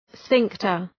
{‘sfıŋktər}